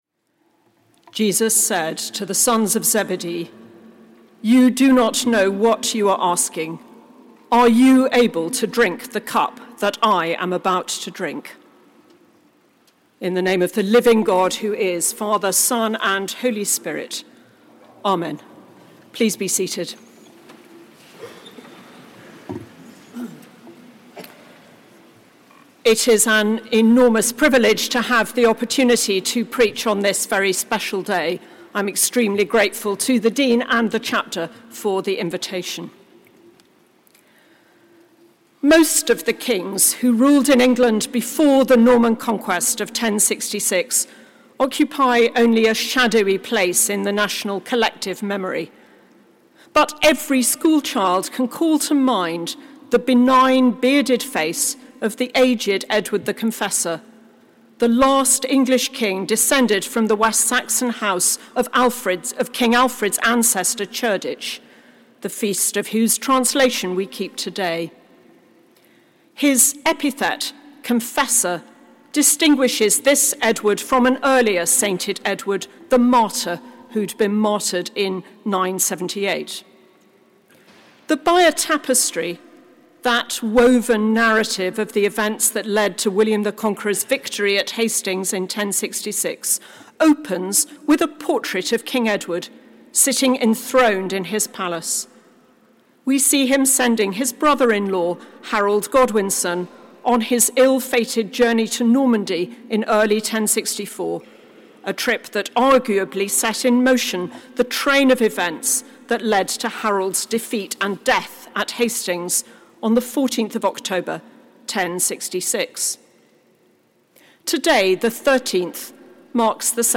Sermon given at the Sung Eucharist on The Translation of St Edward the Confessor